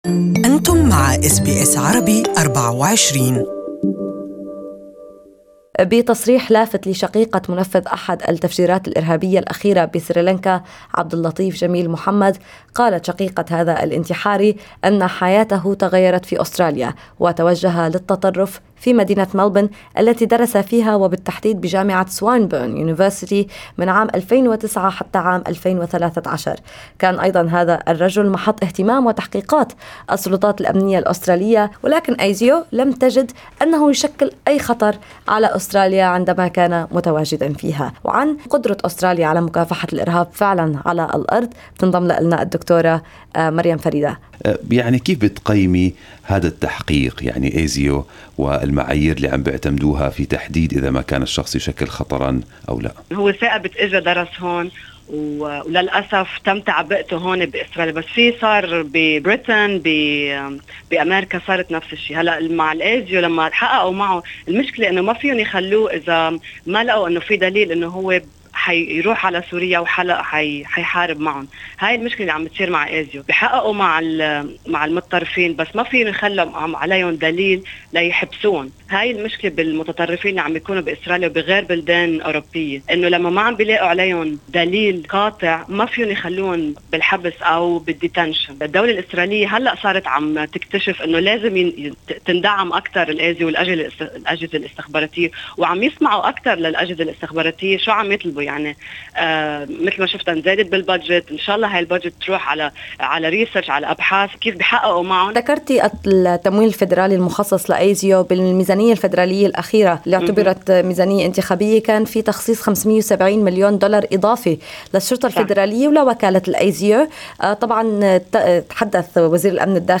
'ASIO should approach local communities': Counter-terrorism expert assesses intelligence in Australia